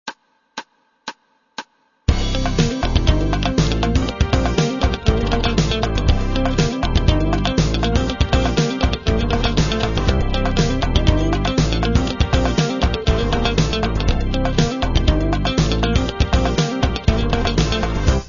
(71кб) и 1slow.mp3 (37кб)- соответственно в оригинальном и медленном темпах.
Первая особенность (она относится и ко всей фанк-музыке в целом)- обширное использование глухих нот, то есть нот, извлекаемых при неприжатой к ладу струне и не имеющих четко выраженной высоты звука, но придающих линии дополнительную акцентировку.
Такой прием использован для того, чтобы еще больше подчеркнуть атаку звука- она будет выделяться за счет полного отсутствия сустейна.
Пример гитарного фанк-аккомпанемента 1
Оба такта играются на фоне аккорда C7, который, естественно, может быть обогащен различными добавочными ступенями.